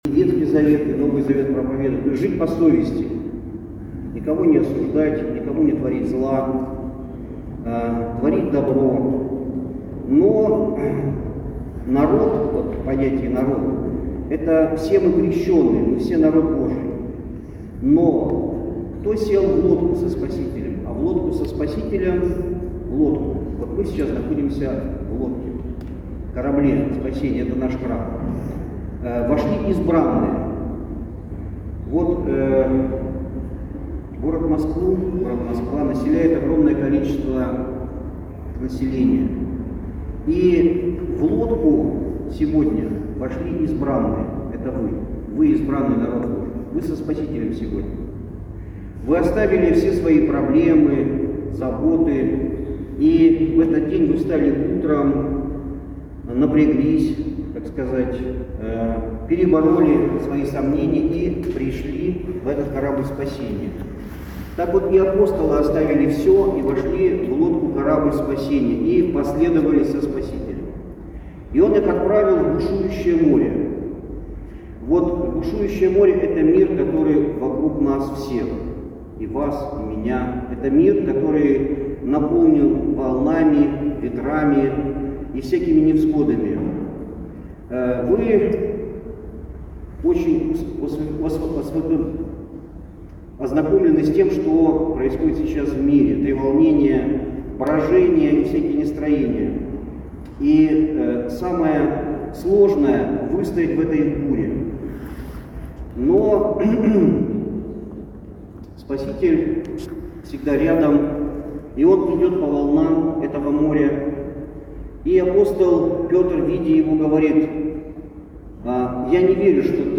Храм Великомученика и Победоносца Георгия в Ендове